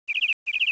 bird3.wav